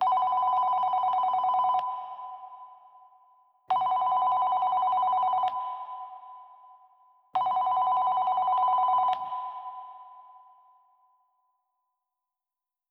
phone_ringing_Astra.wav